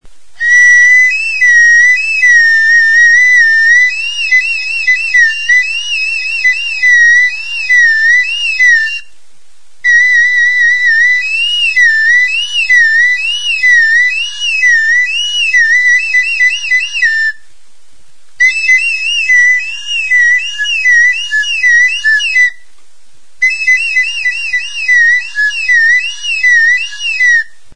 Aerófonos -> Flautas -> Recta (de una mano) + flautillas
Lizar makilarekin egindako txulubita da.
MADERA; FRESNO